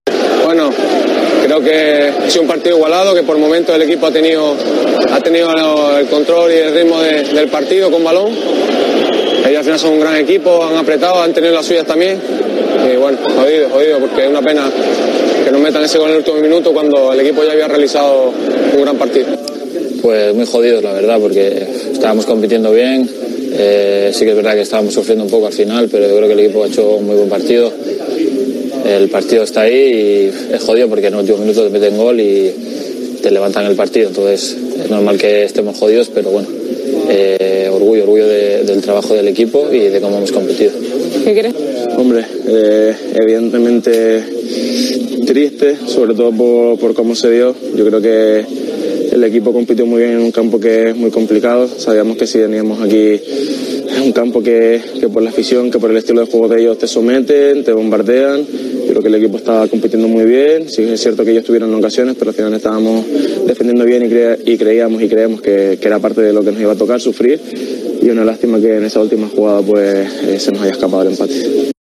ctv-3x2-lvaro-valles-sergi-cardona-y-coco-hablaron-tras-el-encuentro